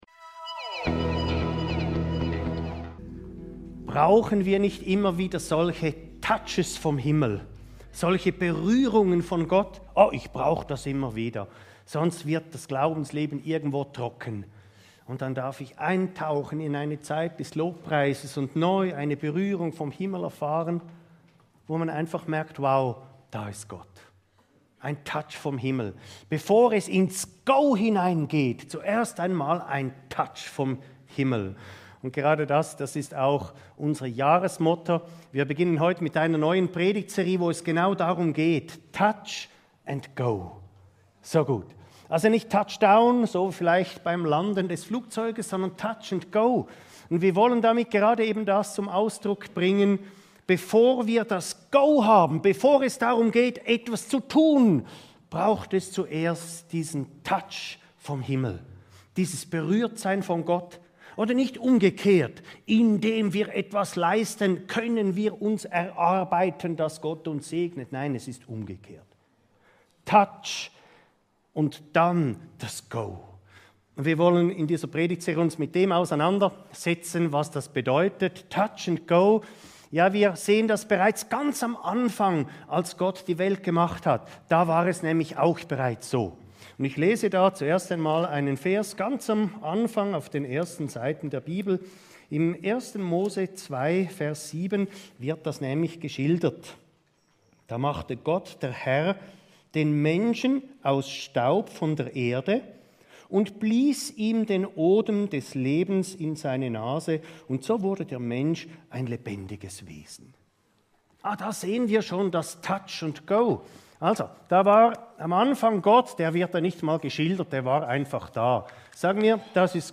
Selfcheck – Leben in der Lifebalance ~ Your Weekly Bible Study (Predigten) Podcast